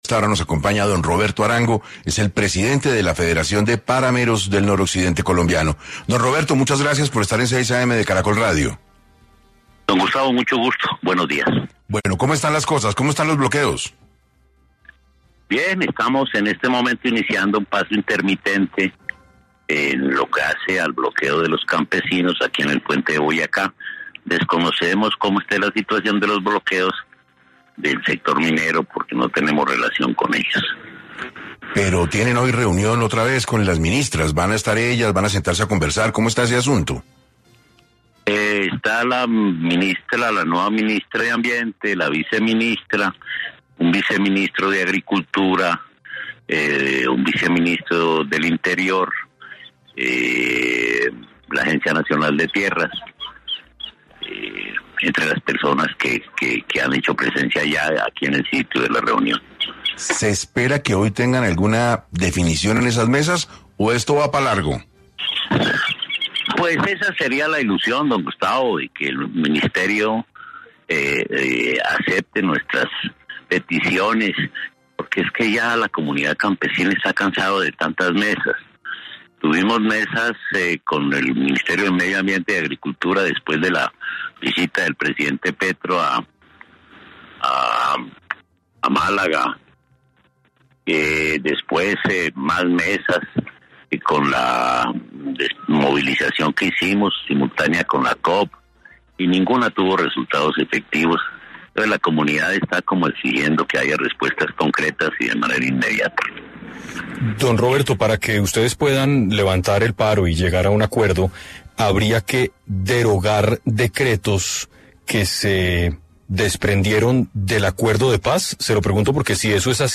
Asimismo, en una entrevista que le concedió a Caracol Radio, aseguró que ya no solo es un problema que le compete a la gobernación, si no al Gobierno Nacional.